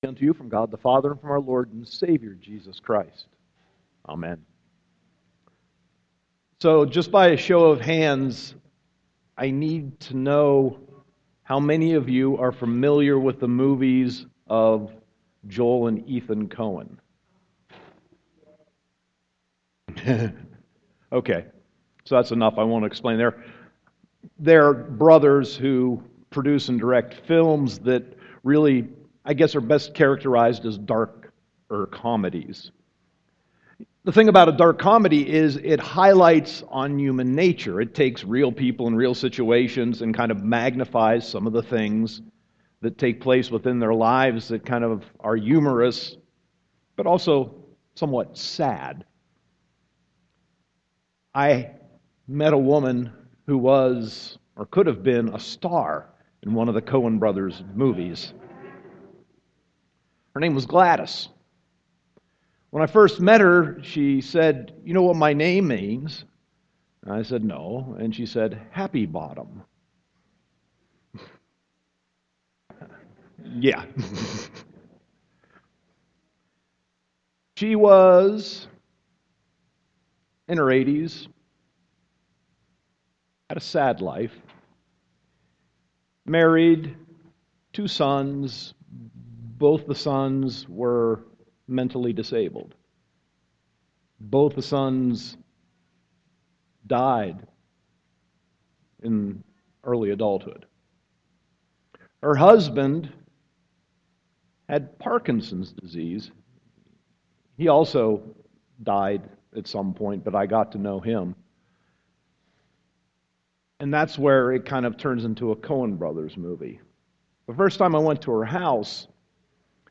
Sermon 10.11.2015